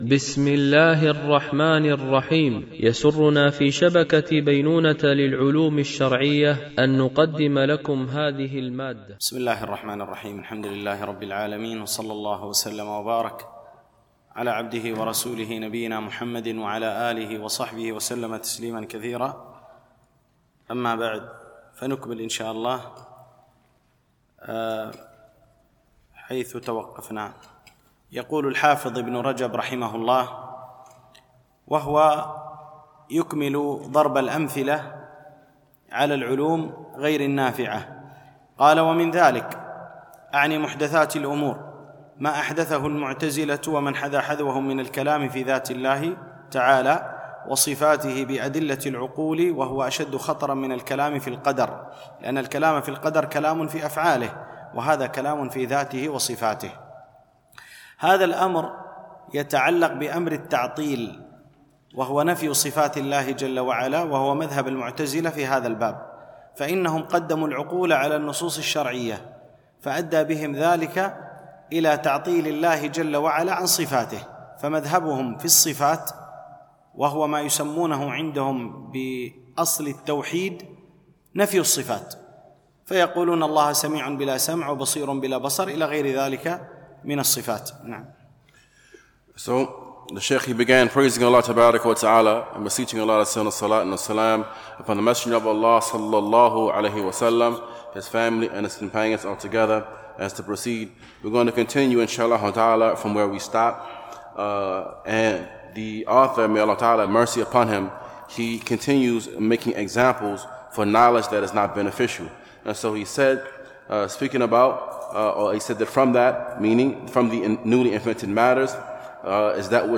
الدورة العلمية الثانية المترجمة للغة الإنجليزية، لمجموعة من المشايخ، بمسجد أم المؤمنين عائشة رضي الله عنها